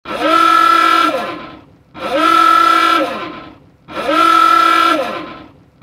Звуки дирижабля
Сигнал в капитанской гондоле